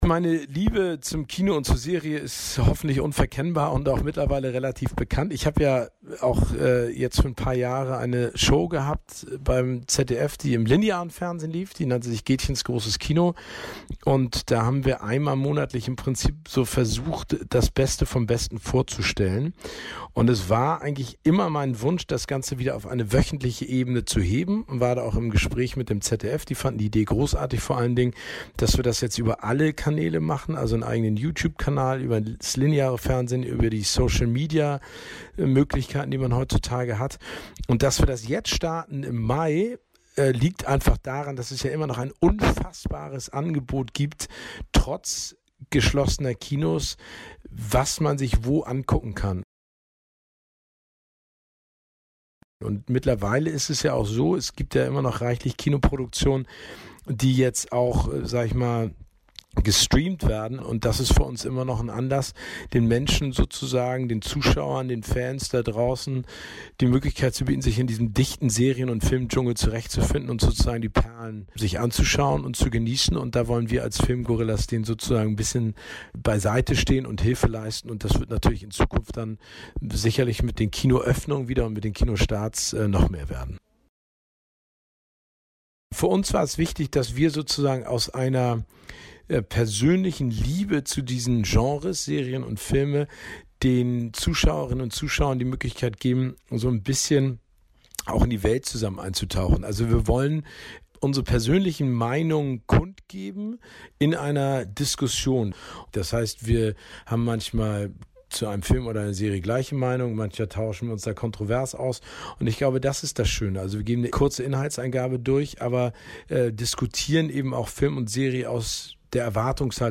zdf_filmgorillas-interview-gaetjen.mp3